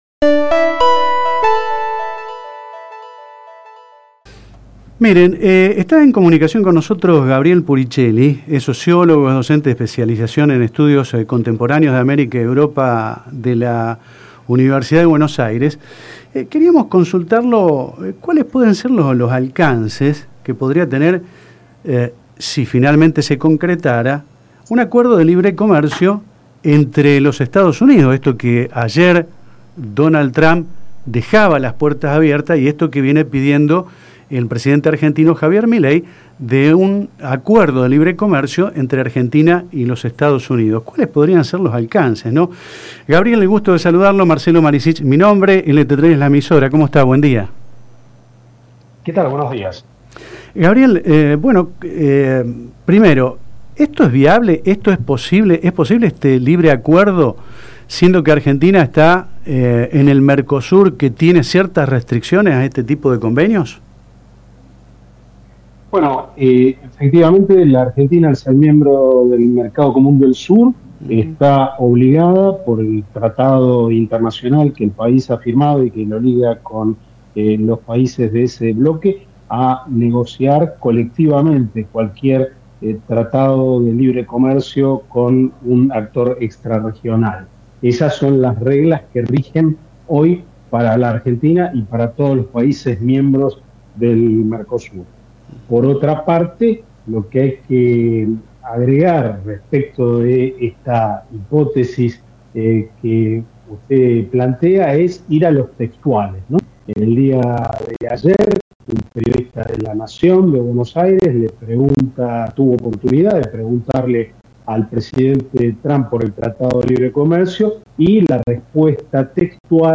En diálogo con LT3